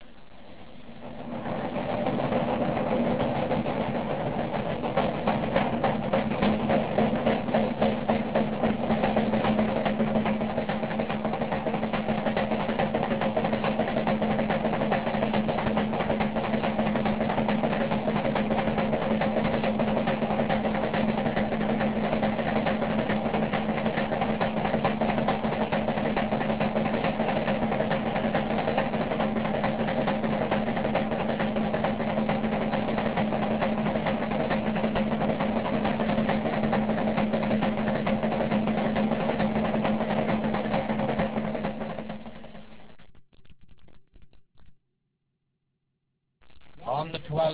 THE SOUNDS OF THE LAMBEG THE TRADITIONAL DRUM OF WILLIAM III AND THE ORANGEMEN AND ULSTER FILL THE AIR AS ALL COME TO ATTENTION:
drum.wav